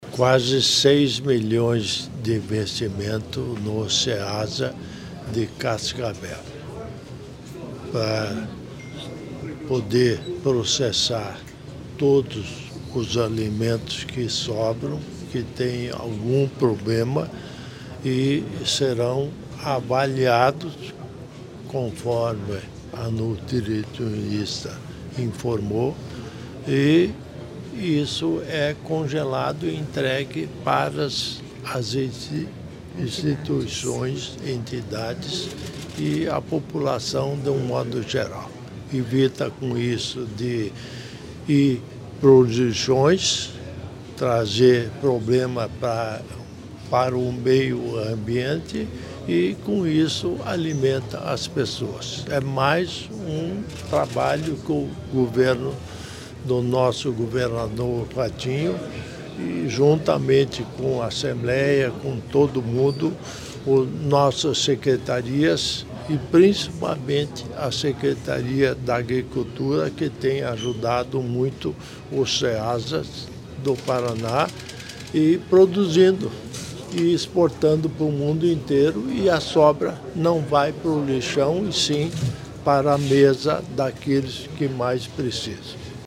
Sonora do governador em exercício Darci Piana sobre a modernização do Banco de Alimentos da Ceasa Cascavel | Governo do Estado do Paraná